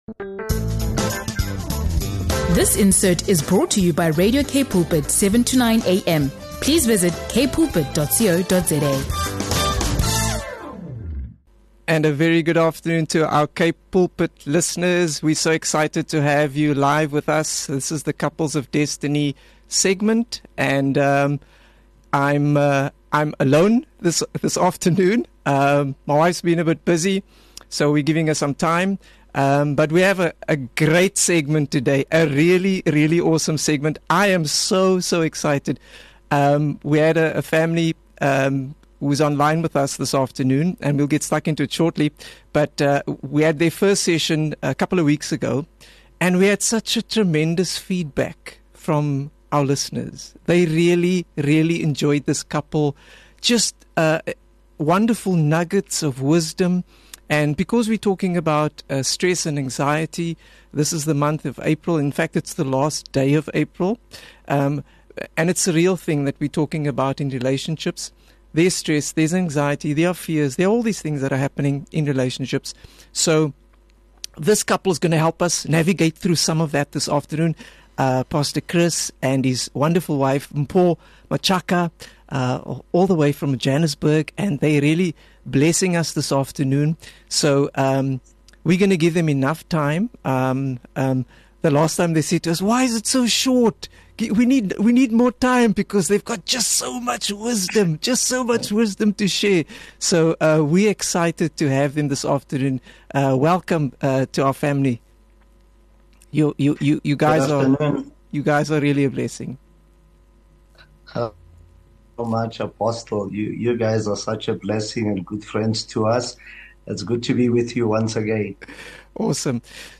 hosts Pastors